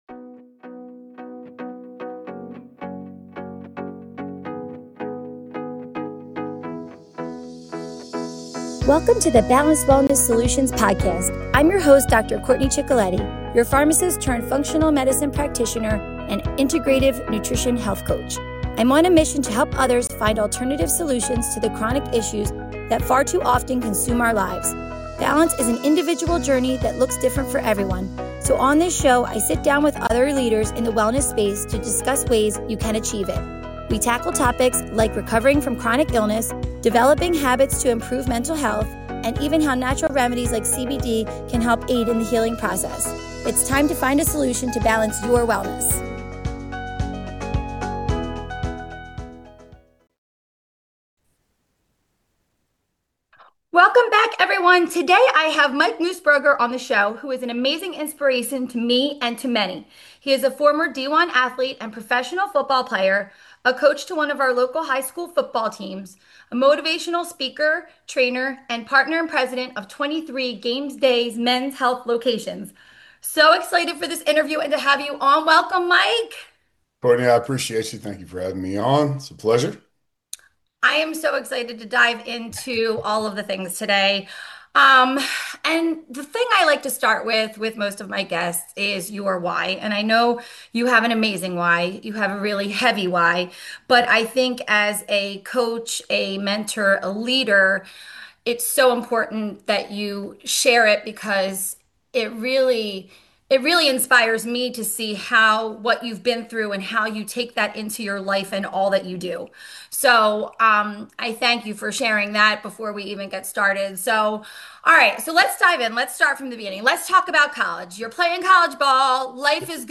This is a powerful conversation for anyone who cares about men’s health, leadership, and breaking the stigma around vulnerability .